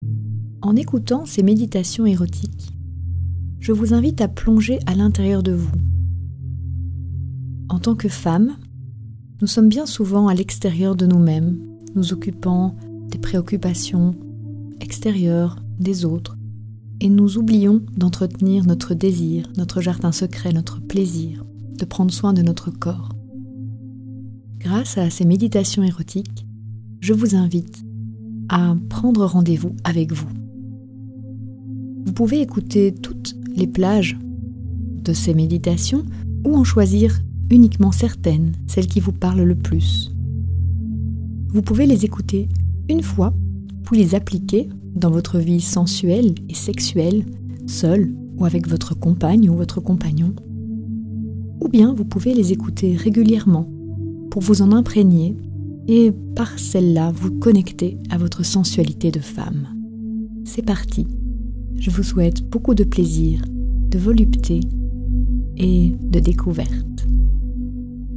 A travers de courtes méditations guidées, nous invitons les femmes à revenir en elles et à se connecter à leur désir et leur sensualité.